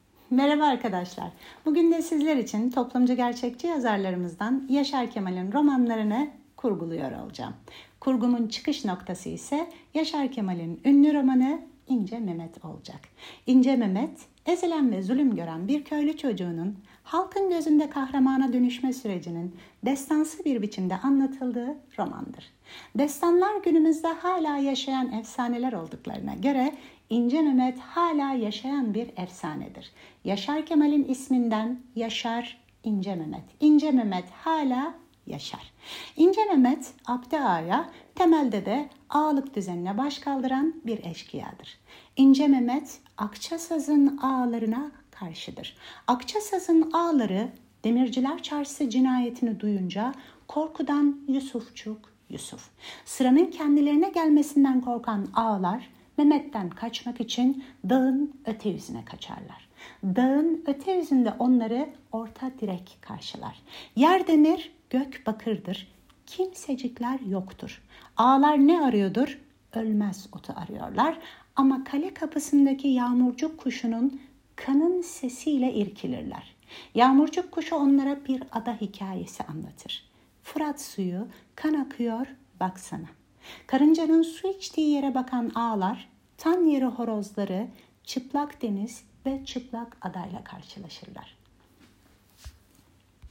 Okuyorum